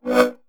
countdown-warn-final.wav